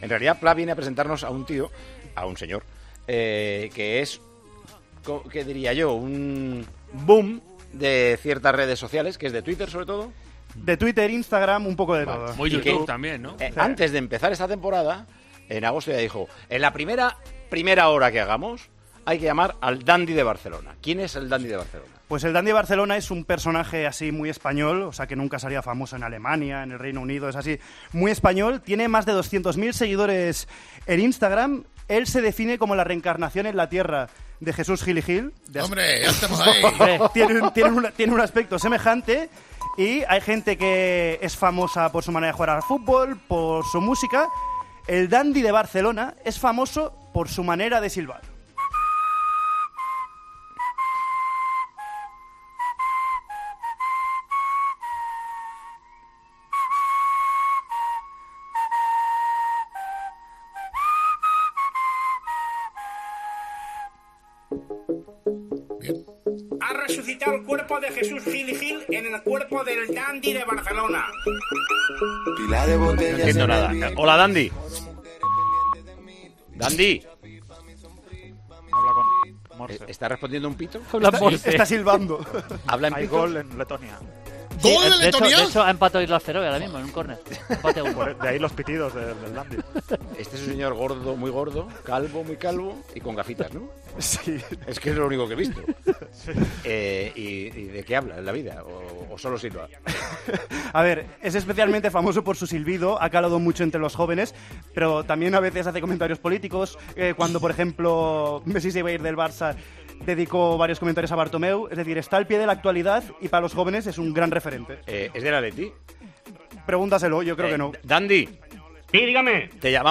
Con su característico silbido, el catalán ha explicado que nadie le ha enseñado a hacerlo, una técnica que ya inunda las redes.
Con Paco González, Manolo Lama y Juanma Castaño